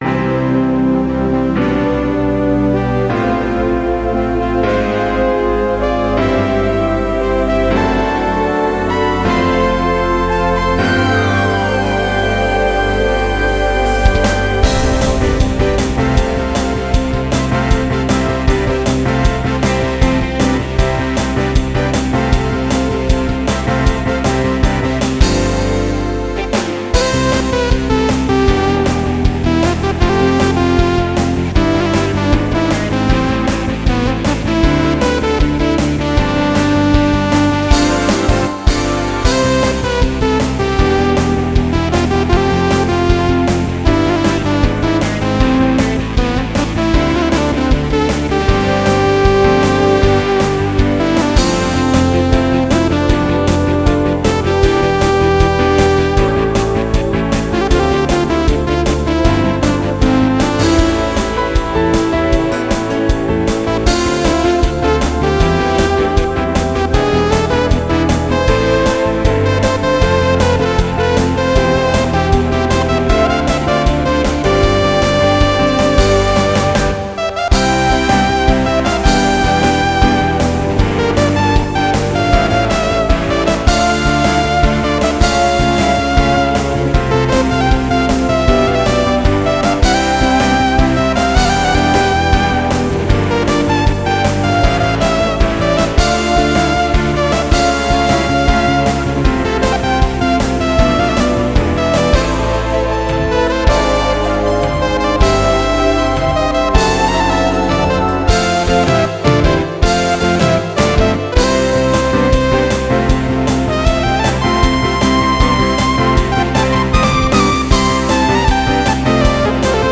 연주가 힘차게 맺고 끊음이 있어서 깔끔하고 시원한 느낌이 들어서 좋네요.
EWI3020
Sound는 EWI3020m 의 S43 Axis 입니다.
녹음은 Cubase 1.52 에서 하였고, 이펙터는 Cubase 기본내장 이펙터를 사용하였습니다.
따라서, 이펙터로 덮어버린 후에도 버벅거림이 많이 느껴집니다.